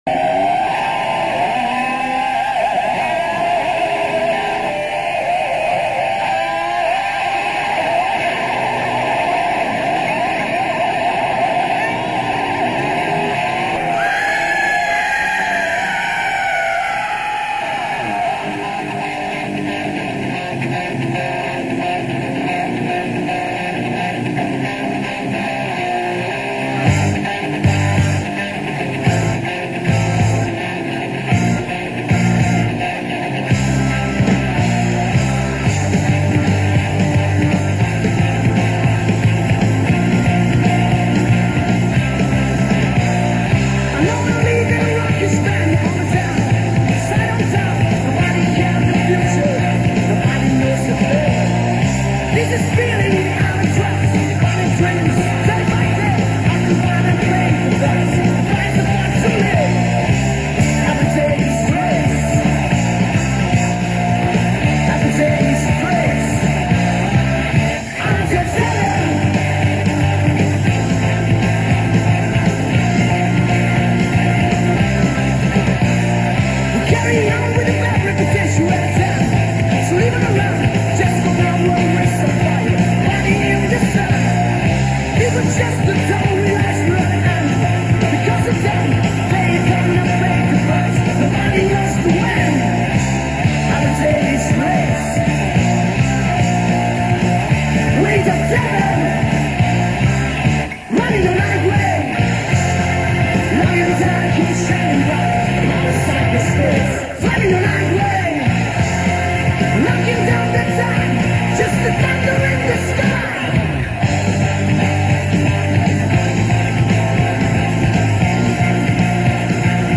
metallari
frammento audio dall'album